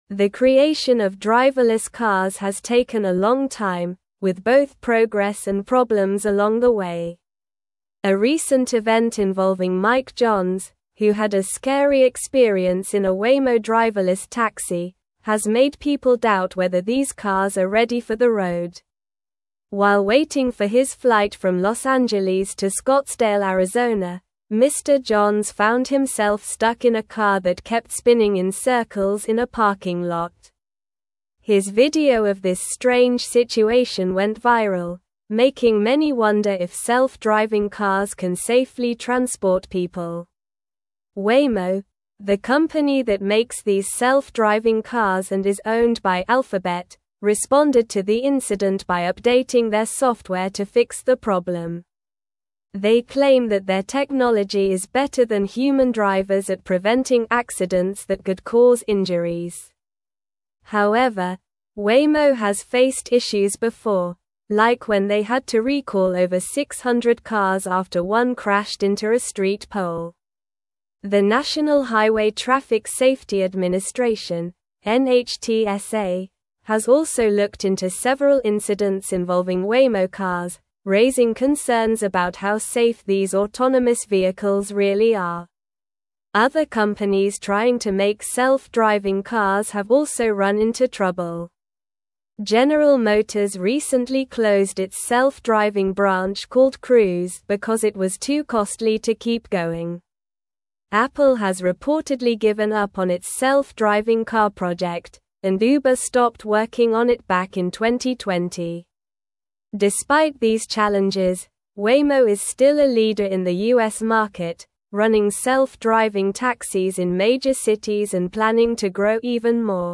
Slow
English-Newsroom-Upper-Intermediate-SLOW-Reading-Challenges-Persist-in-the-Adoption-of-Driverless-Vehicles.mp3